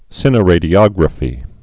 (sĭnə-rādē-ŏgrə-fē)